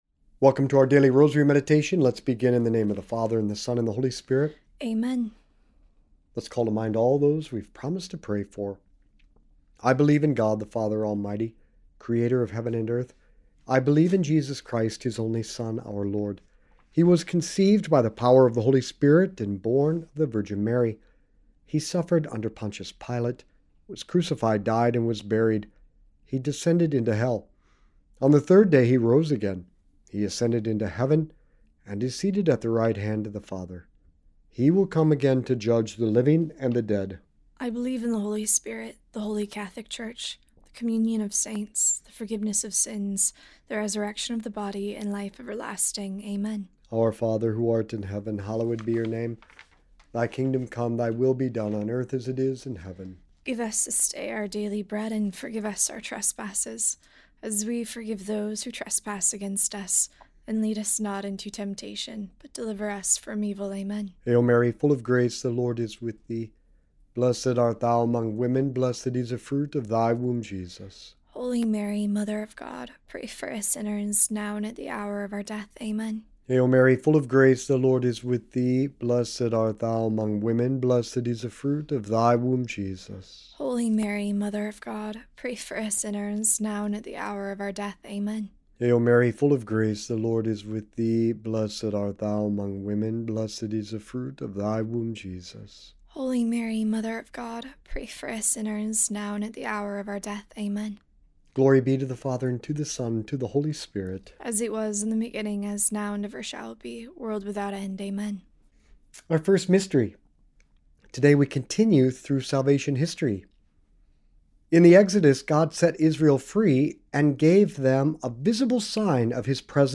This episode is a Catholic rosary meditation focused on the theological connection between Old Testament symbols of God's presence (the pillar of fire and cloud, the Ark of the Covenant) and Mary as the new visible sign of the Holy Spirit in the New Testament.